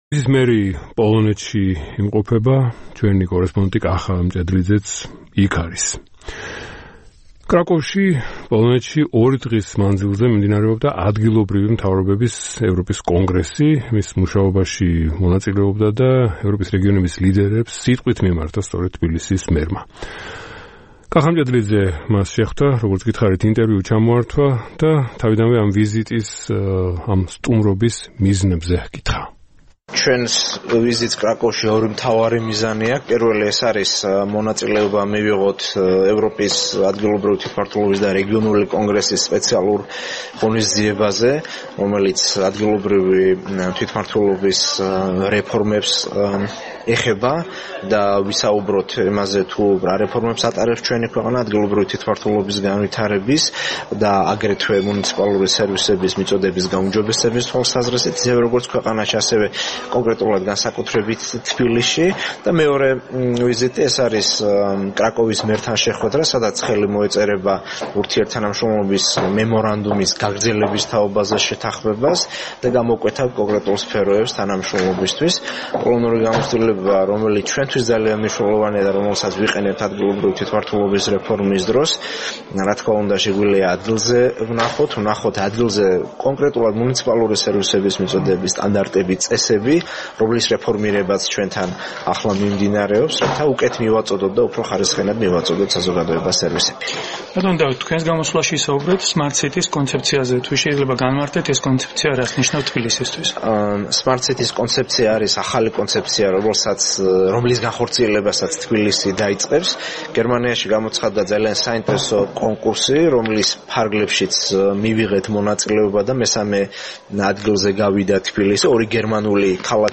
საუბარი დავით ნარმანიასთან